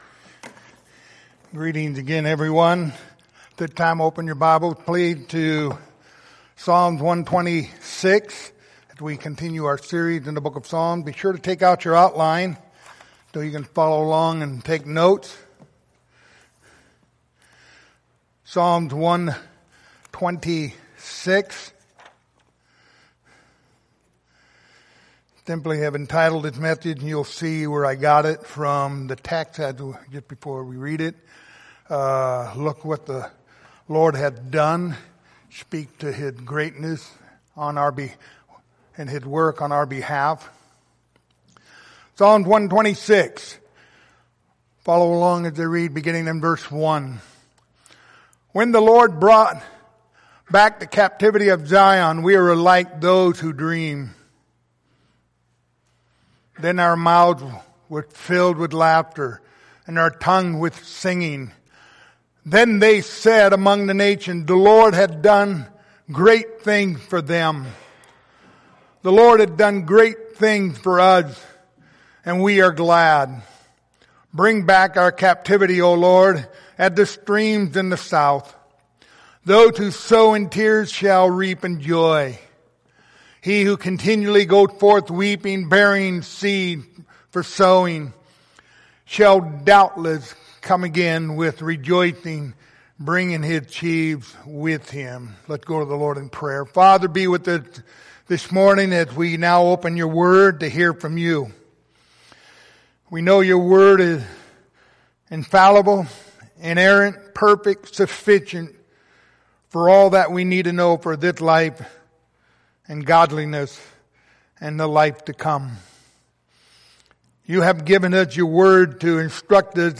Passage: Psalm 126:1-6 Service Type: Sunday Morning Topics